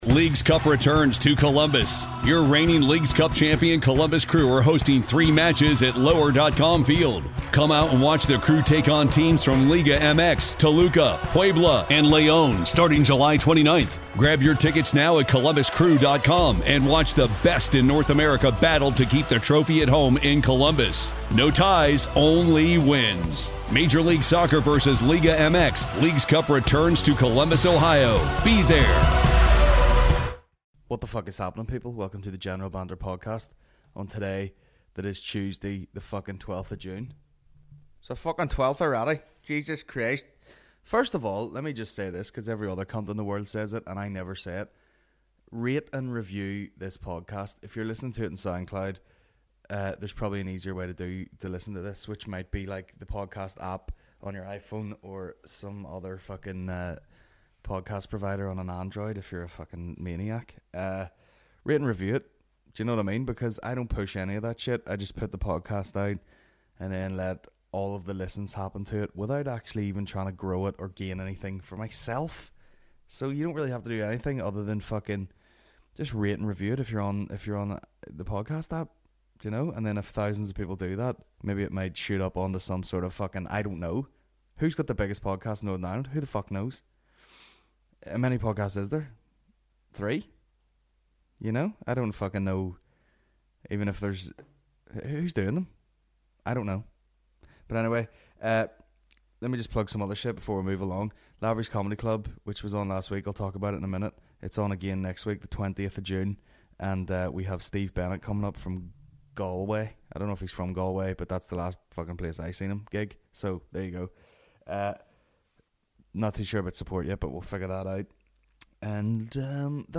Tuesday 12th June 2018 General Banter Podcast General Banter Podcast Comedy 4.8 • 1.1K Ratings 🗓 12 June 2018 ⏱ 90 minutes 🔗 Recording | iTunes | RSS 🧾 Download transcript Summary This week - Solo Shite .